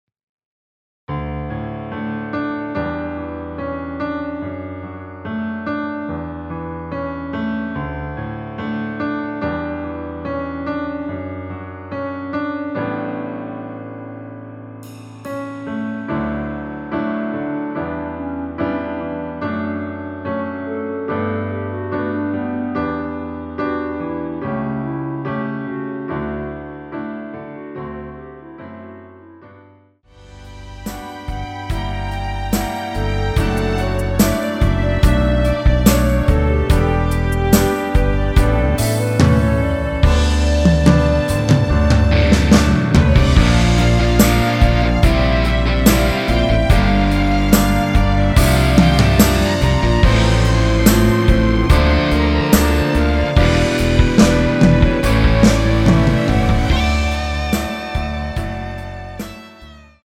원키에서(-1)내린 멜로디 포함된 MR입니다.(미리듣기 확인)
멜로디 MR이란
앞부분30초, 뒷부분30초씩 편집해서 올려 드리고 있습니다.
중간에 음이 끈어지고 다시 나오는 이유는